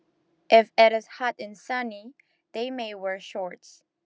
Latin_American_Speaking_English_Speech_Data_by_Mobile_Phone